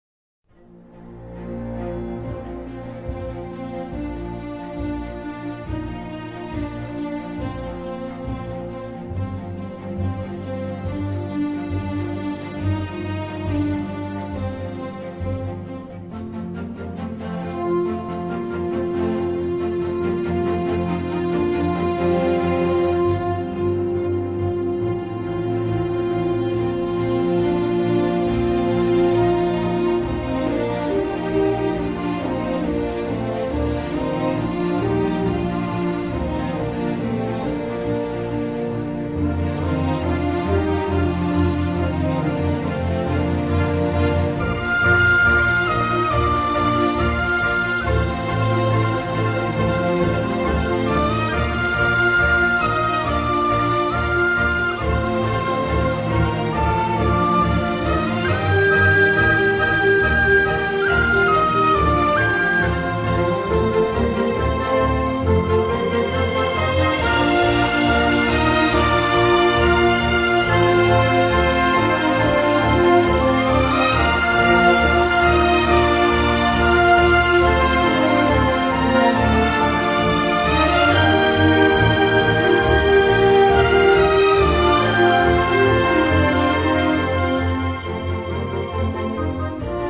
La música es de un exuberante clasicismo.